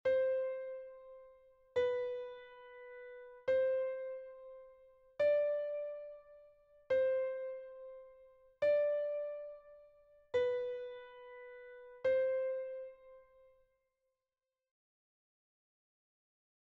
note recognition exercise 2
2_note_recognition_clave_sol_D_agudo_8_notas.mp3